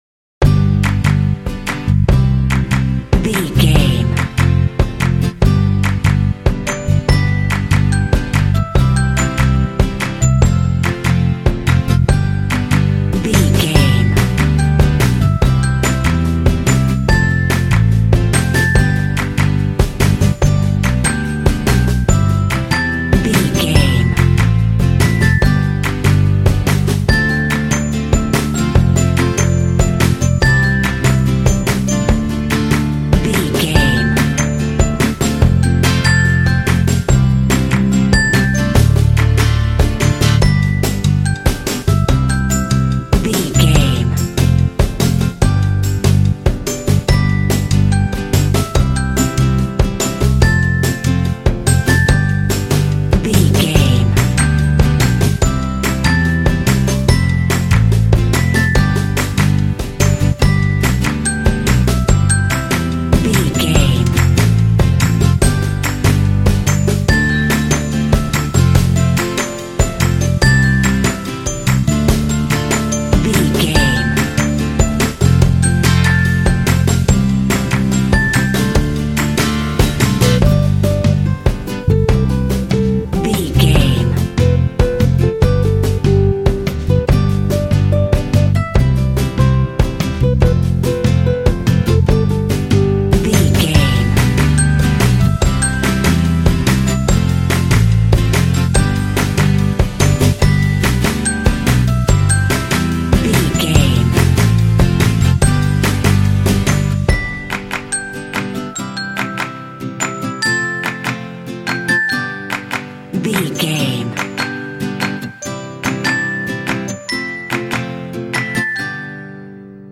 This cute pop underscore is great for kids and family games.
Uplifting
Ionian/Major
bright
happy
wholesome
optimistic
joyful
acoustic guitar
percussion
drums
bass guitar
pop
contemporary underscore